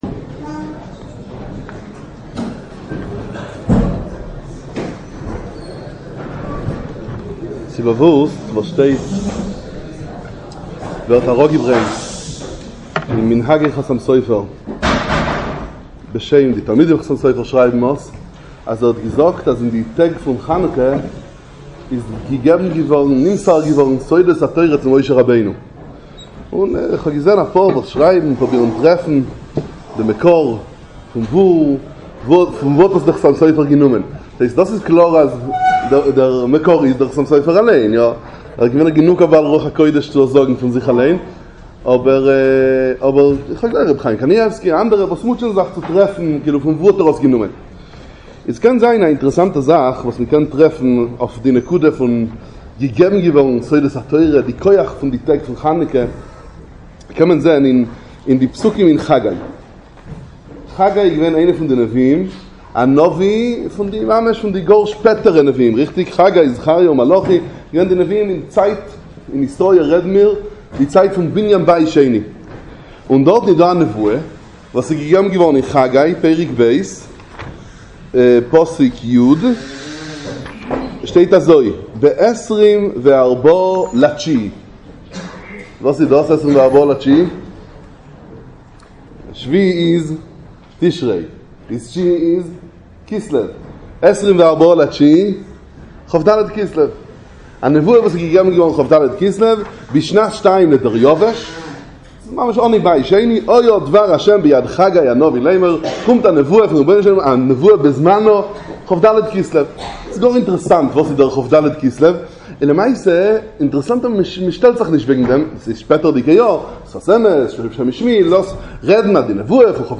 דרשת הכנה לחנוכה